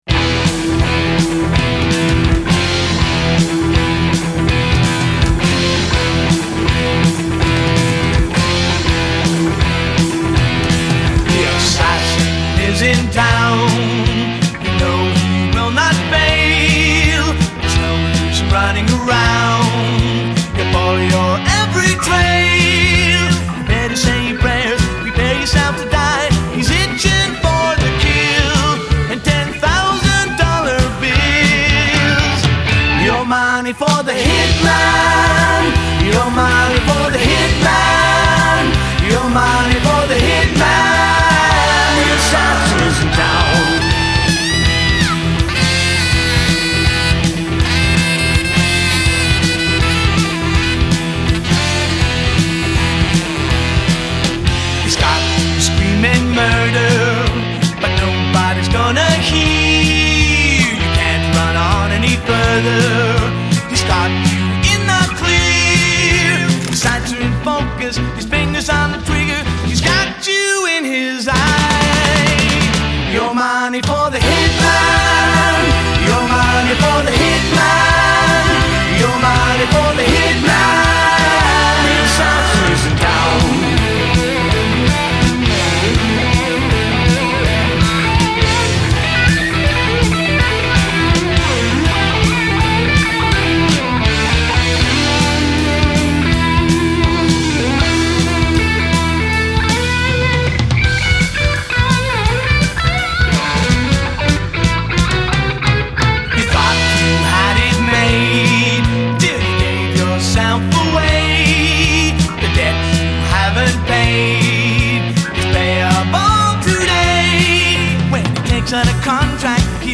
This is the funniest song on the album, it's honestly not too bad but it's very clear the guy never left the 60's: